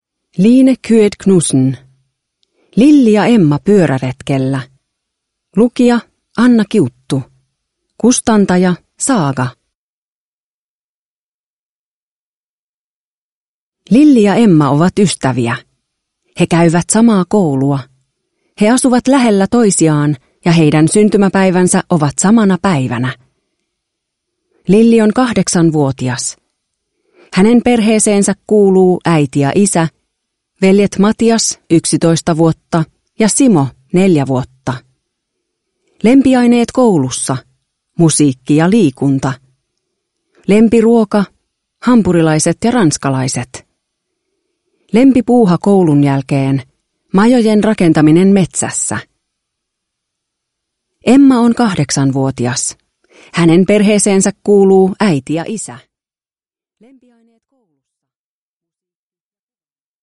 Lilli ja Emma pyöräretkellä (ljudbok) av Line Kyed Knudsen | Bokon